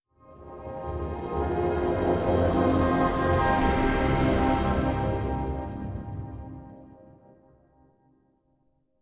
OS3 Warp 2.0 Startup.wav